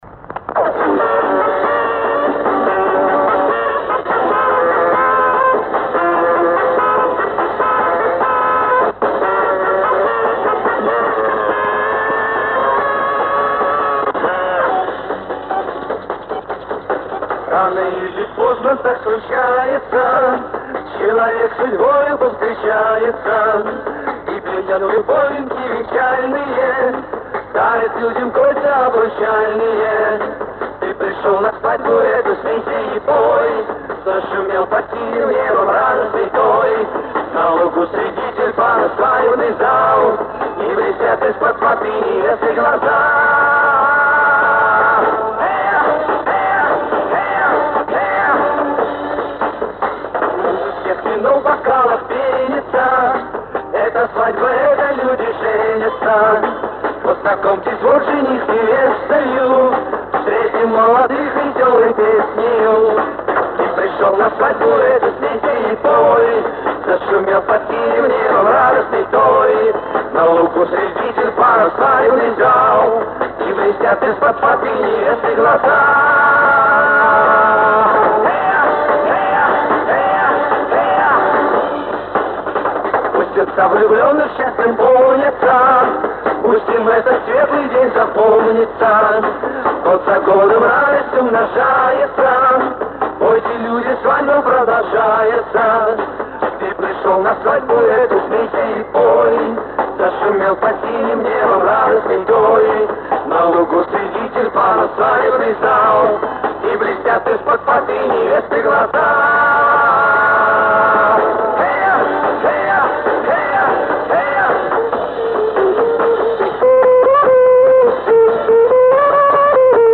Качество оставляет желать лучшего, но именно  эта песня бала на рёбрах, исполнитель неизвестен.